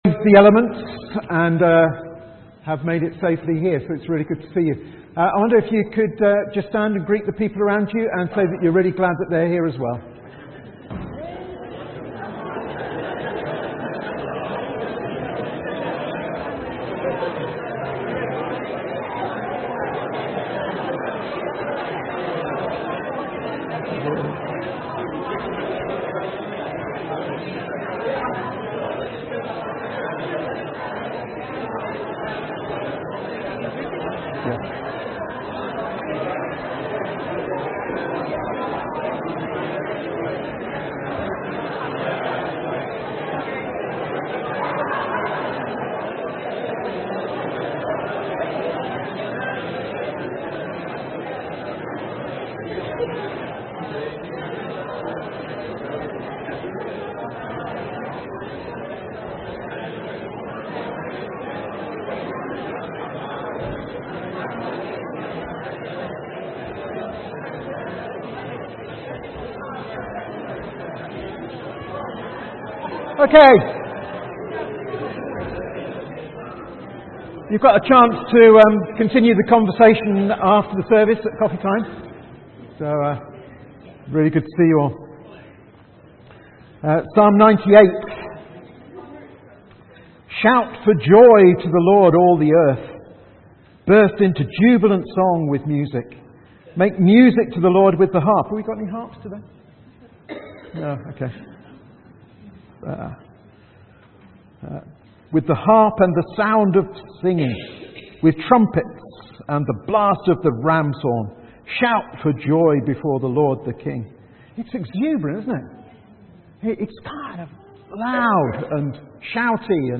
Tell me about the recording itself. From Service: "10.45am Service"